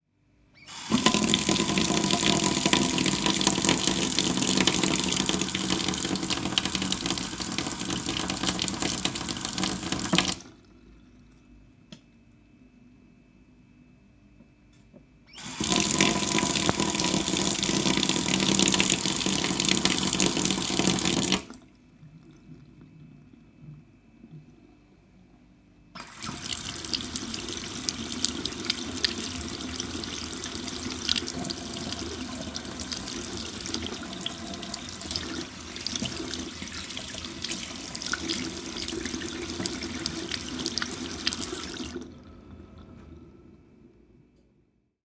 水龙头响亮的单声道夹子
描述：水龙头自来水。
标签： 厨房 自来水 湿 水槽 飞溅 跑步 滴水 洗衣机 水龙头
声道立体声